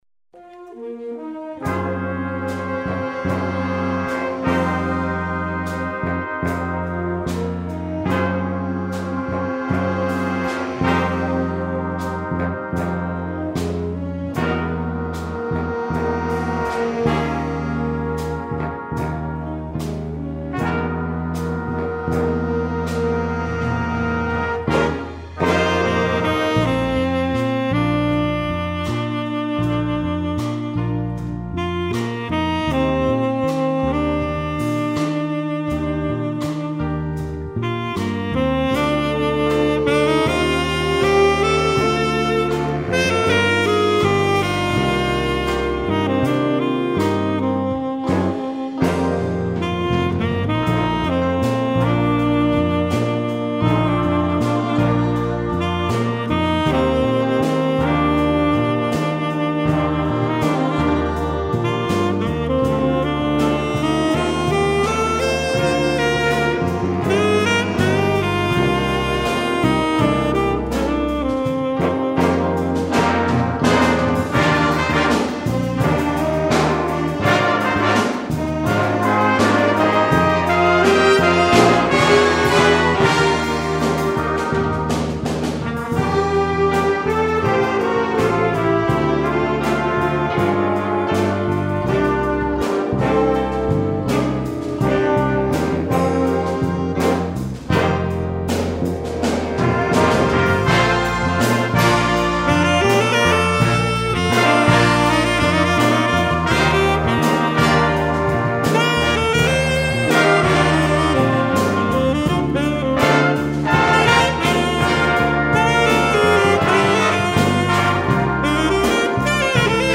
a gorgeous rock ballad featuring tenor saxophone